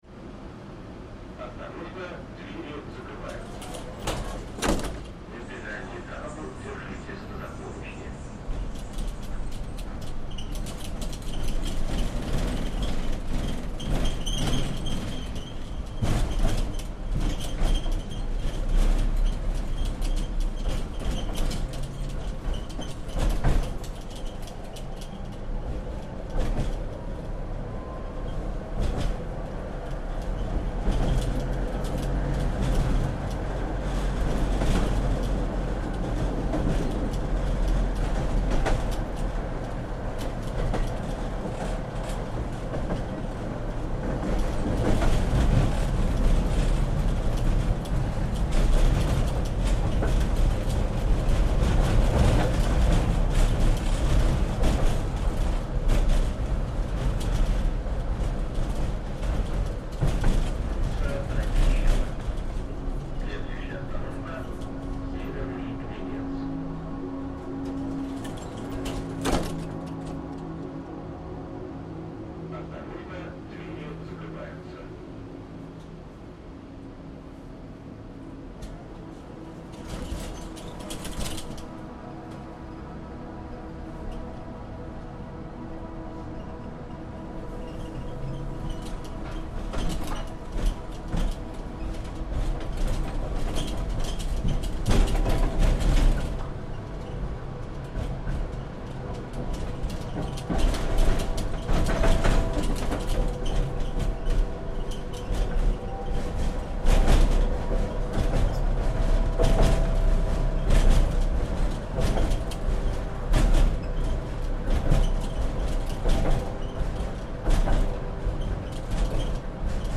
Streetcar in Ulyanovsk reimagined